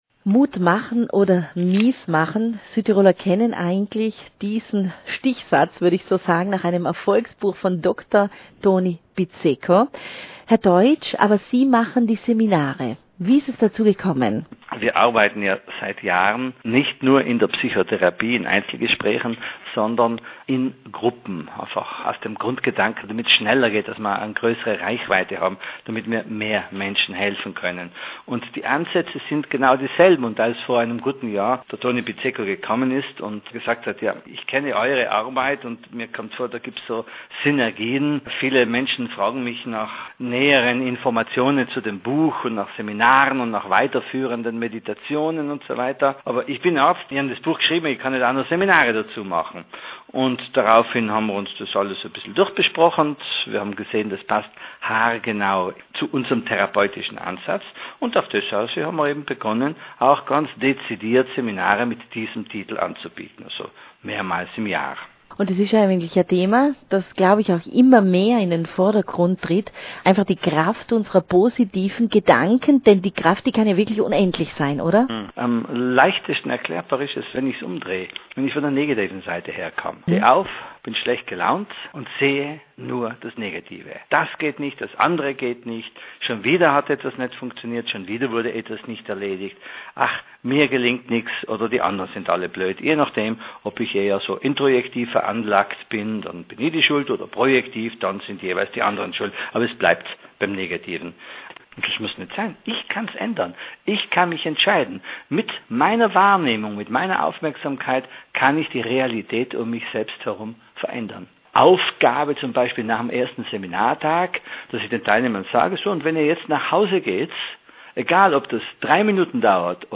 Positive Gedanken – die Radiosendung: Ausschnitte aus der Sendung, Dauer ca. 3 Minuten.